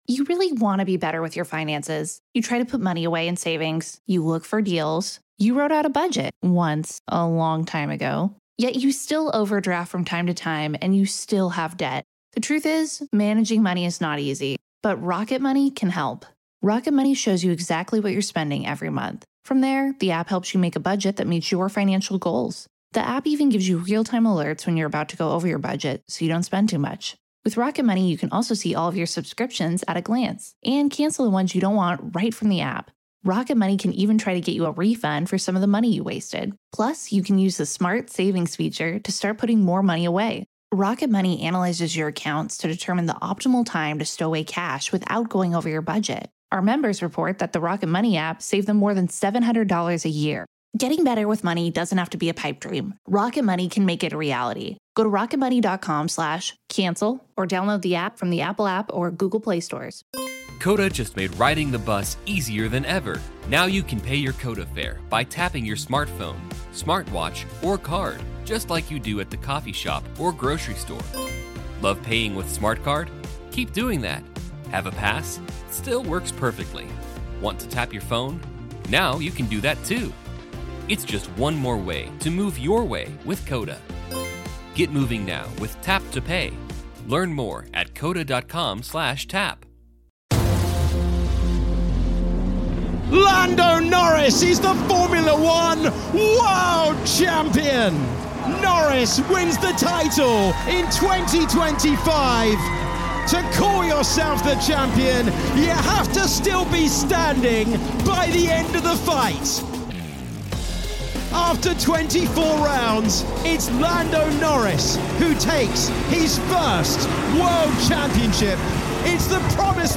You’ll hear reaction from the man himself, as he talks about turning his ‘struggles into strengths’ and winning this title 'the Lando way’.